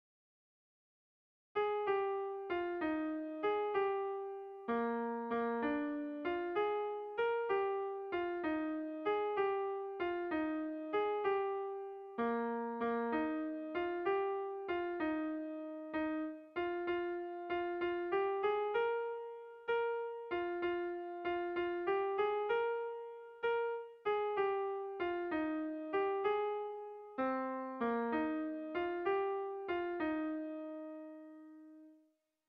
Air de bertsos - Voir fiche   Pour savoir plus sur cette section
A1A2BA2